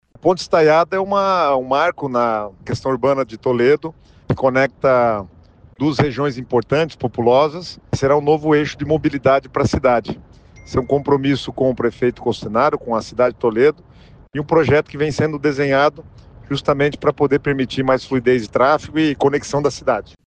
Sonora do secretário das Cidades, Guto Silva, sobre o investimento de R$ 45 milhões para construção de ponte estaiada em Toledo